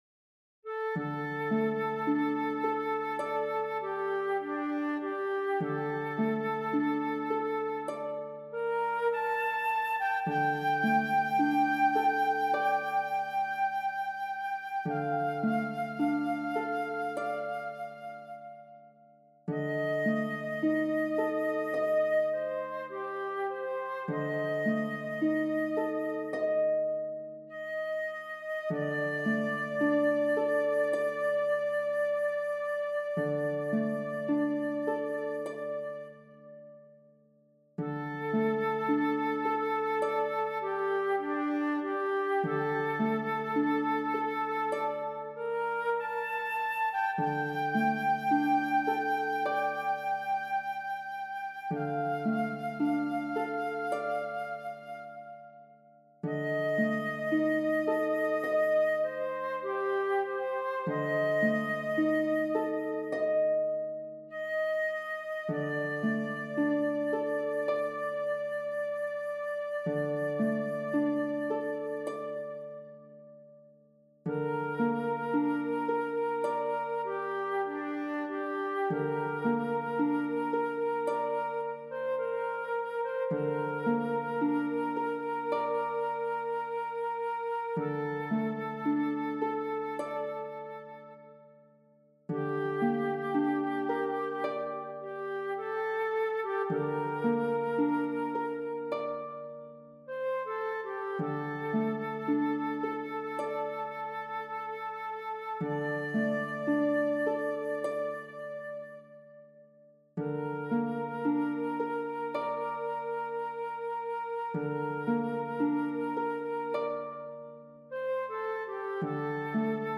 田舎風小曲集） Flute / Harp